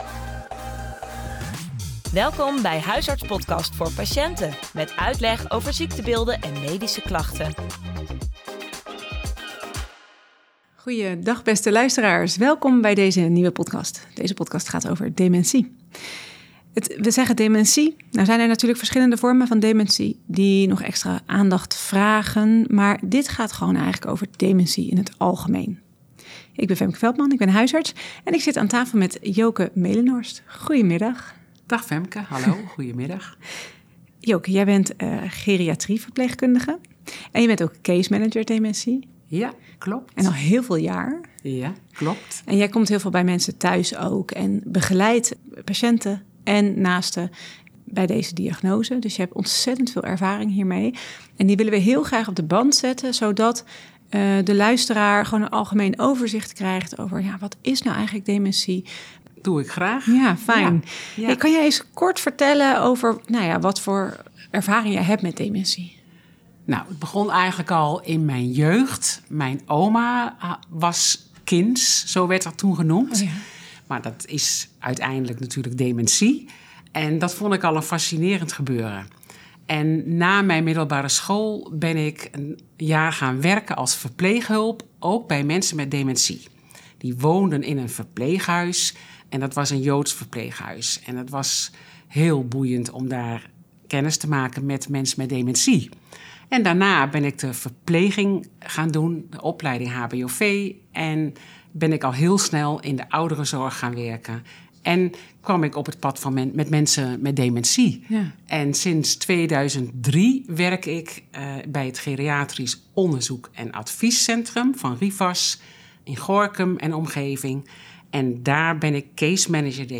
Een interview